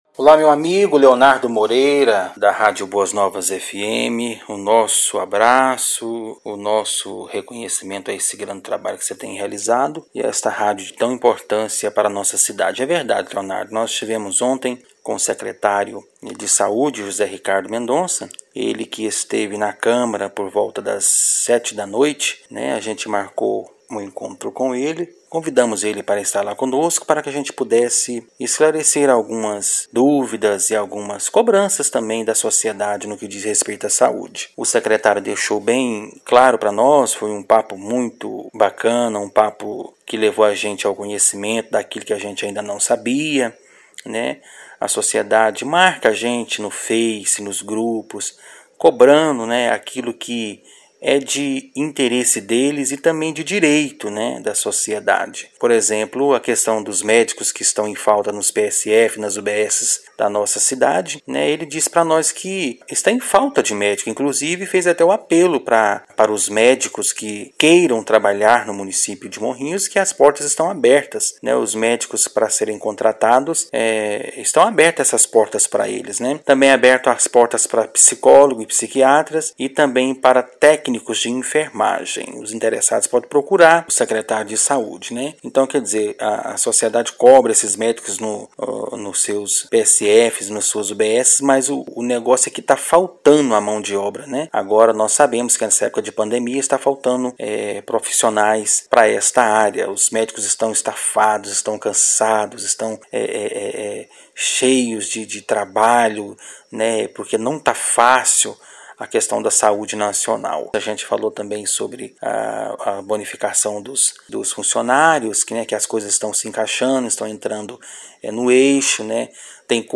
O vereador Cristiano Cardoso (DEM) fala sobre a reunião, ouça: